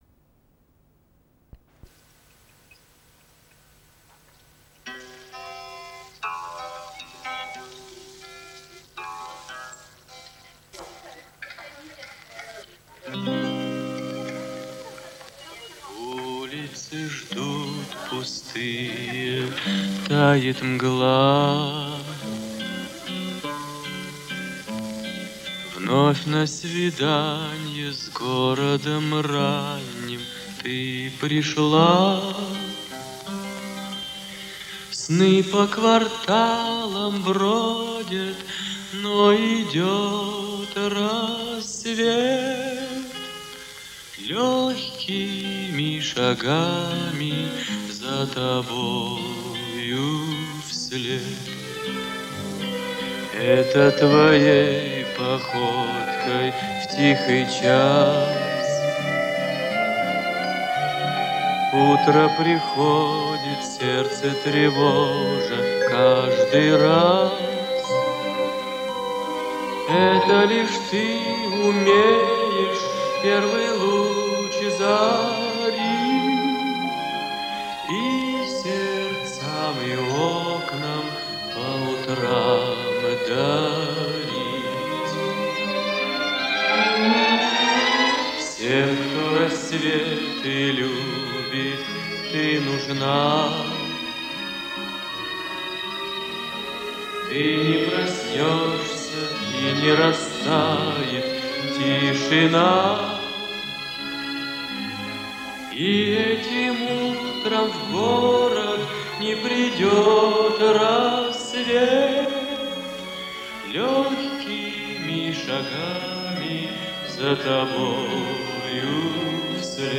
Студенческая песня.
Запись 1958 года  Моно звучание.
Это произведение , запись на магнитной ленте.